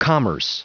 Prononciation du mot commerce en anglais (fichier audio)
Prononciation du mot : commerce